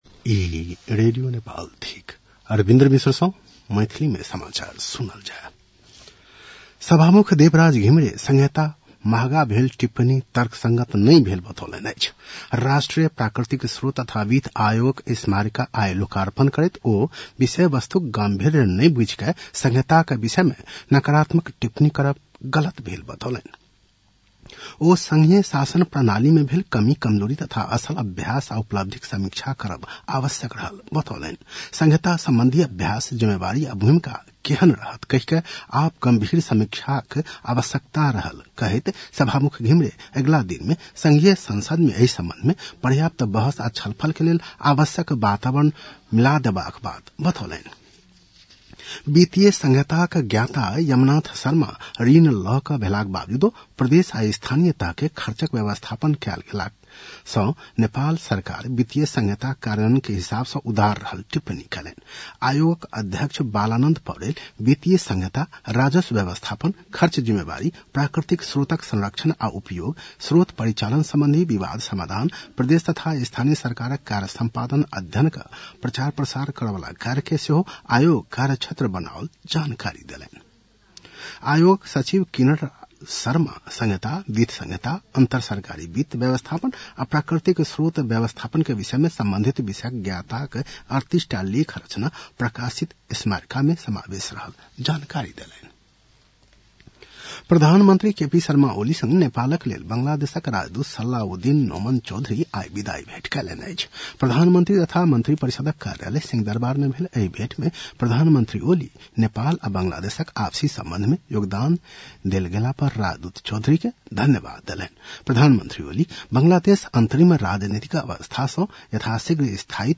मैथिली भाषामा समाचार : ६ पुष , २०८१
6-pm-Maithali-news-9-05.mp3